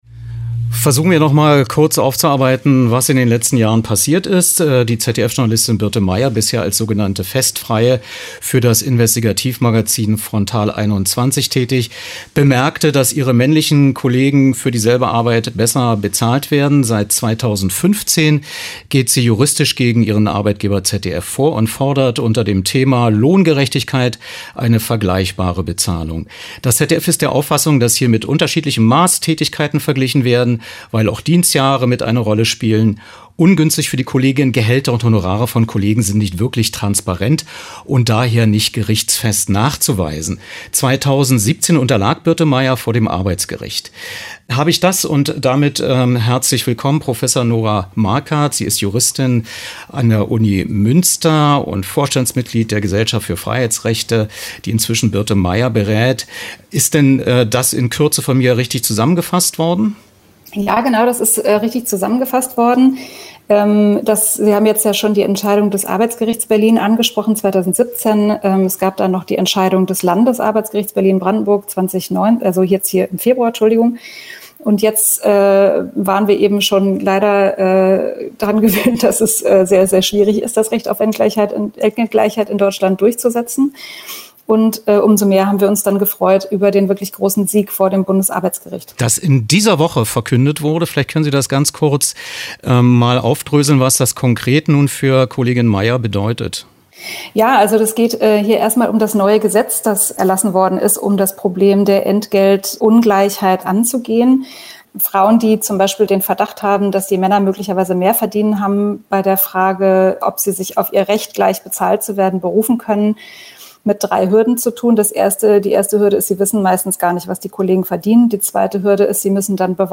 VoIP-Interview
im radioeins-Medienmagazin, rbb